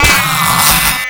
sentry_damage4.wav